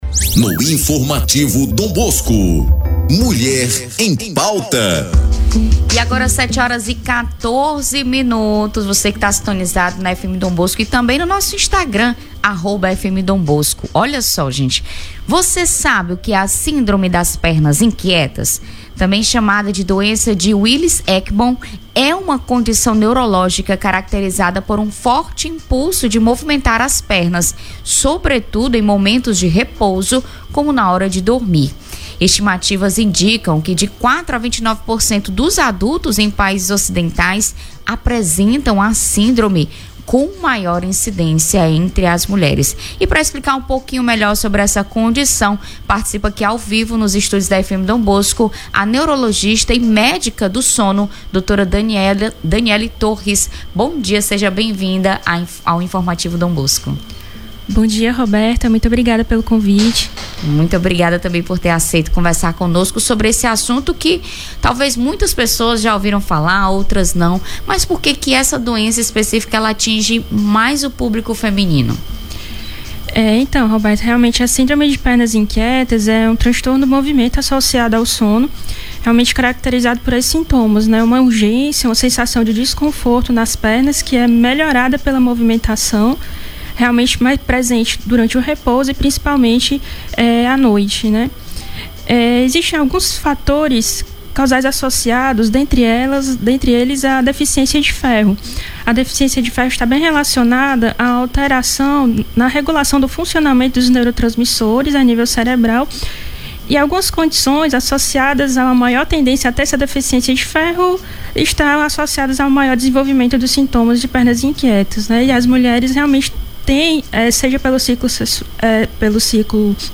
Entrevista do dia